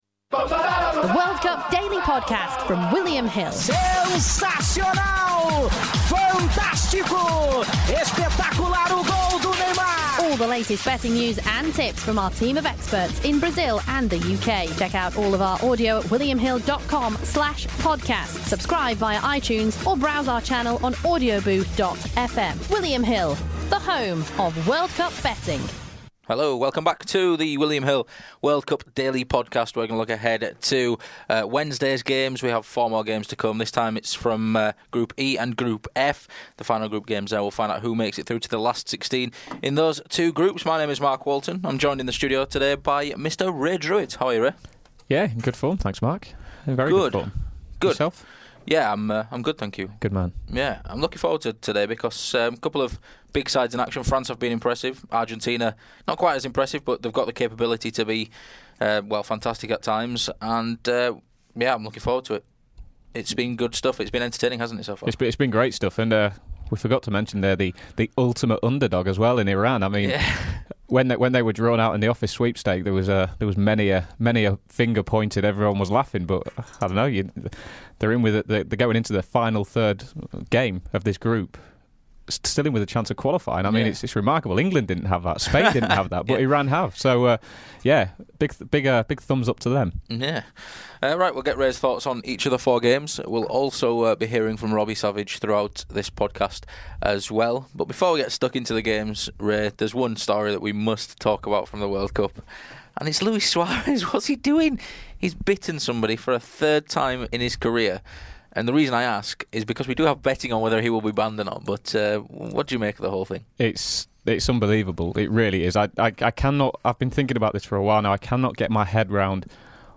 We also hear Robbie Savage's predictions in each match.